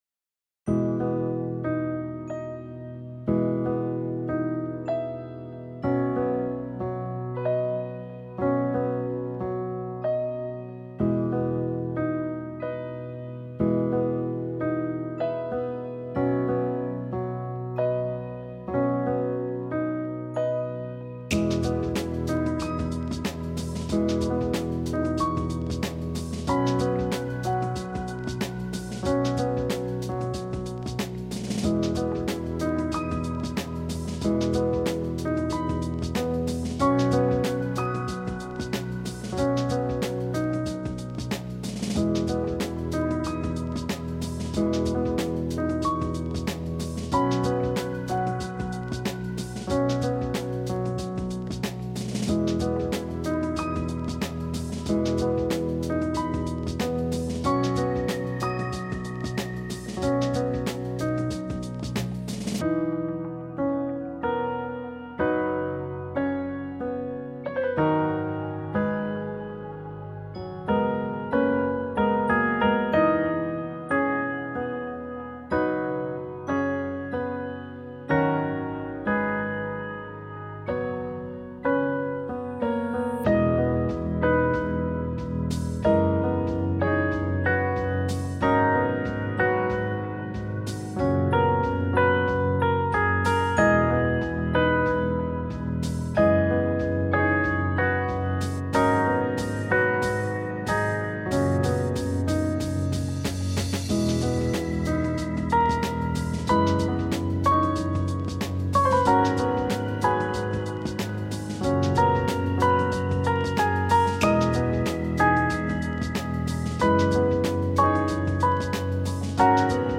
Вечерняя музыка для прогулок в парке